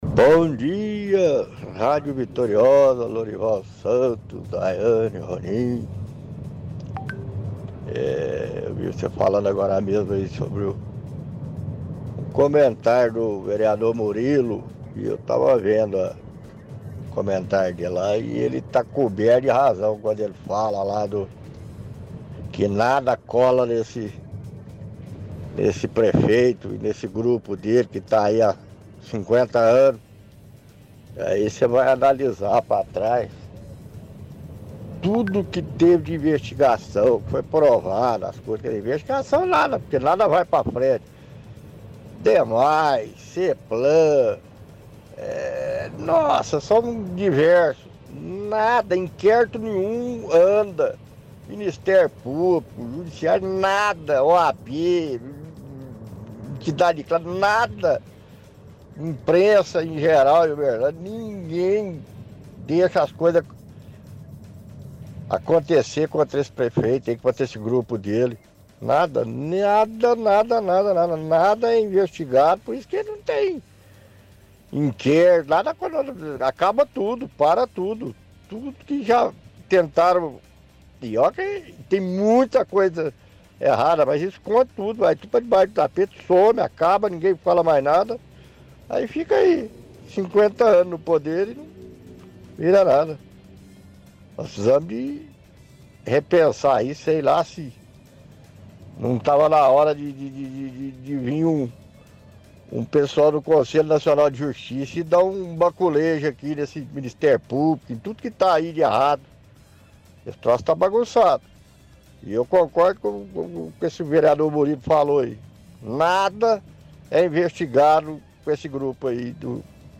– Ouvinte fala que tudo que o Vereador Murilo falou na rádio é verdade.